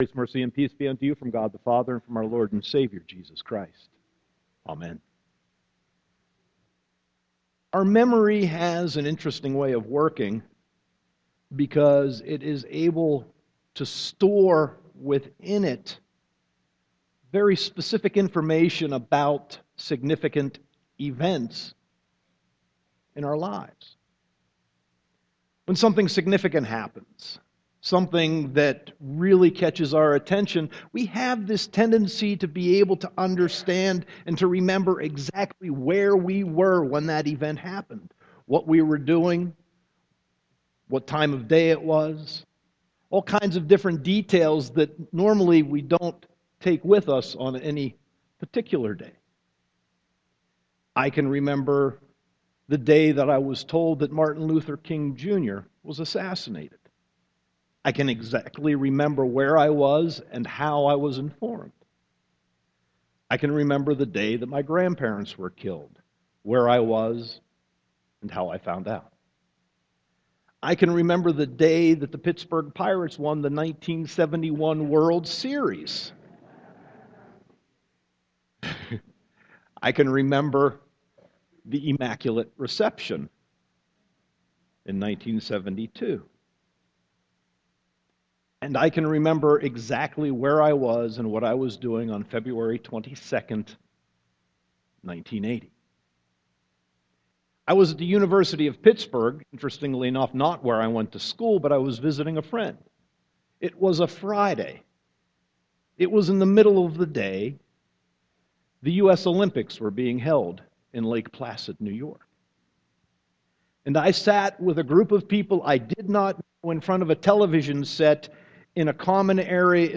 Sermon 8.3.2014